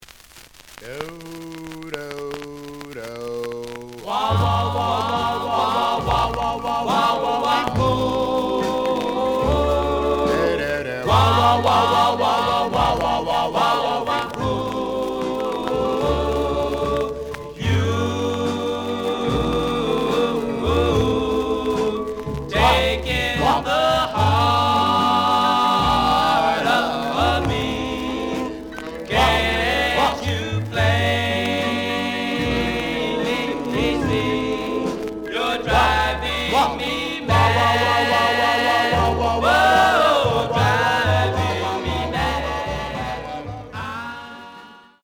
The audio sample is recorded from the actual item.
●Genre: Rhythm And Blues / Rock 'n' Roll
Some click noise on middle of A side due to a bubble.